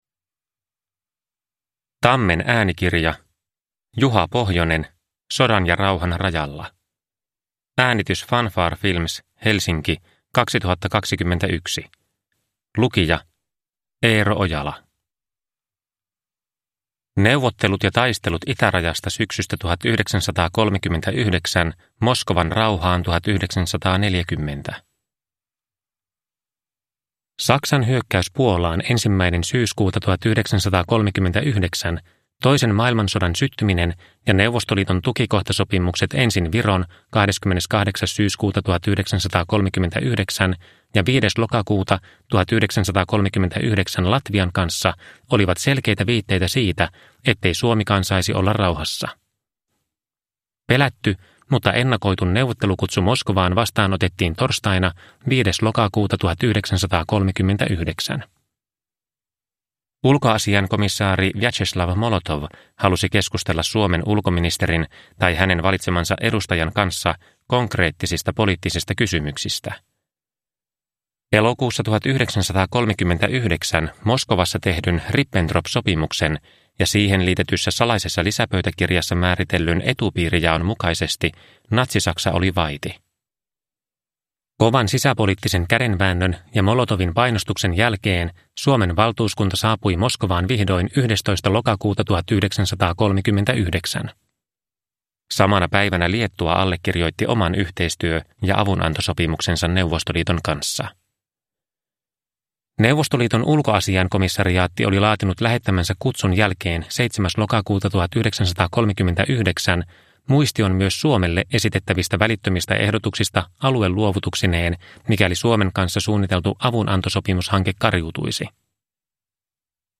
Sodan ja rauhan rajalla – Ljudbok – Laddas ner